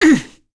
Demia-Vox_Damage_01.wav